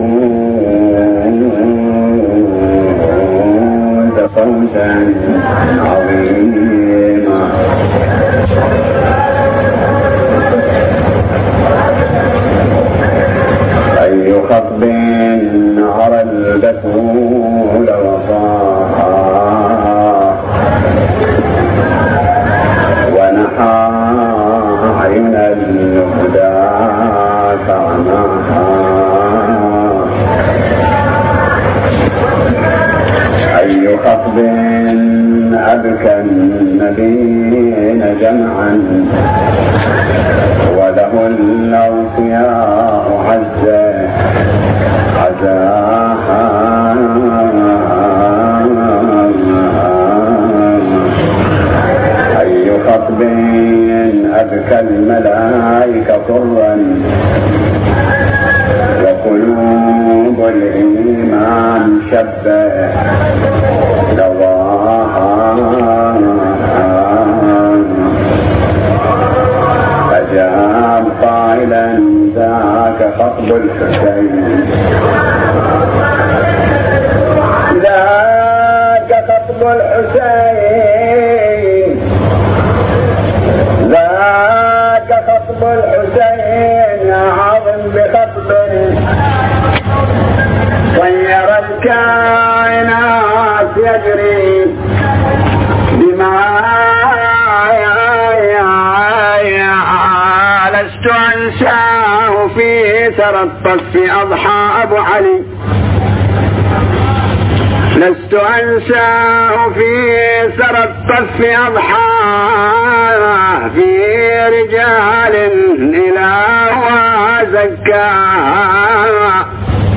مجلس حسيني